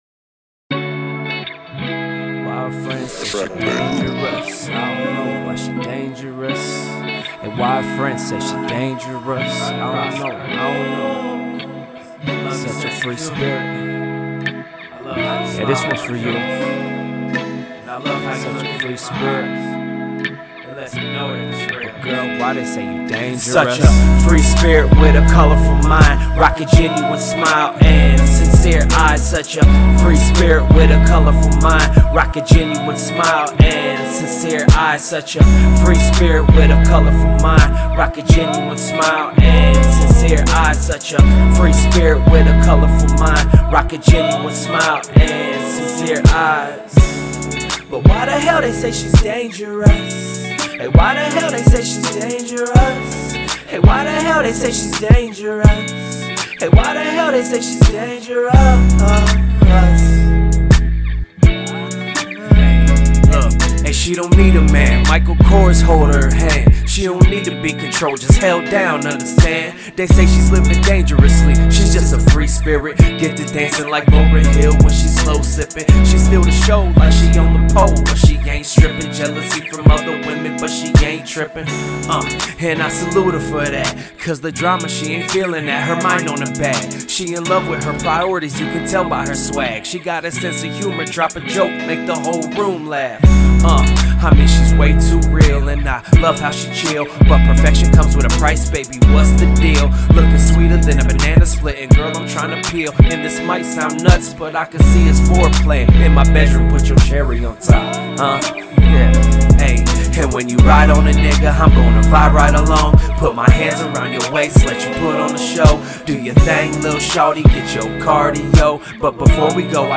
RnB
Description : OHIO Hip-Hop Muzik!!!